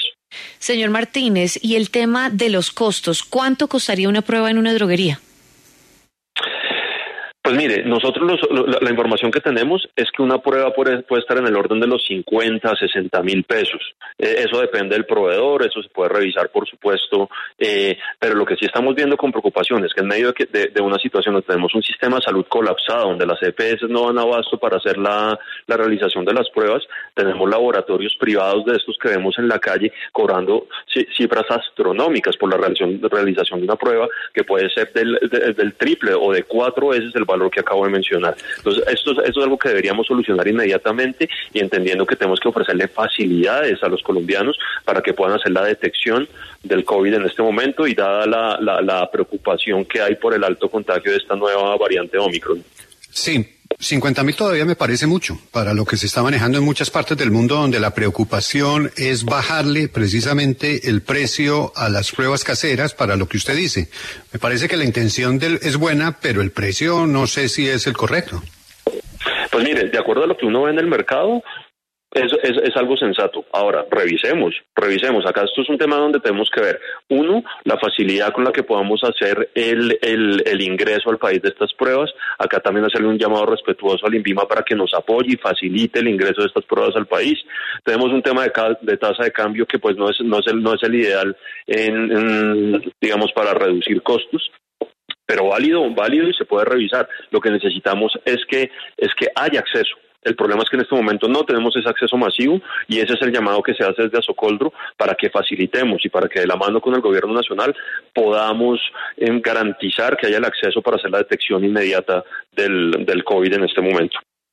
En diálogo con la W Radio